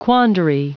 .noun,.plural.quandaries.pronounced 'quan dree(s)'